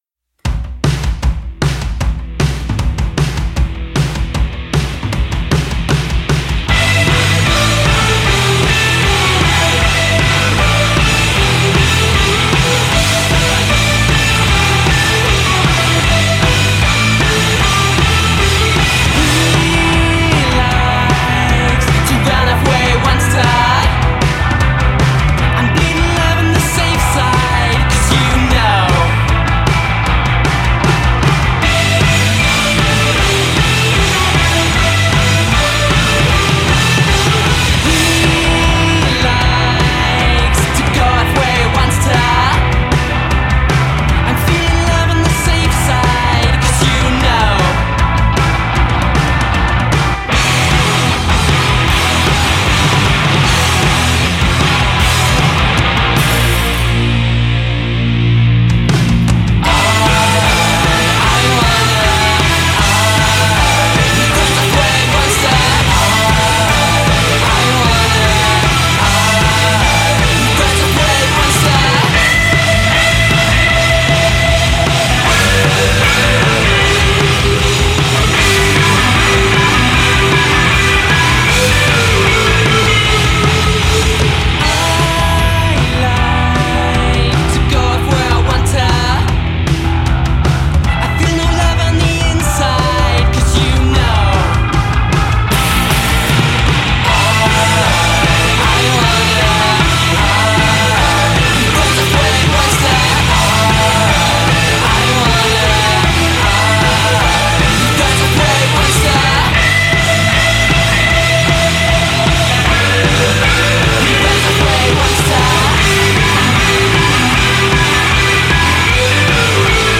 grunge-pop quartet
fiery, exhilarating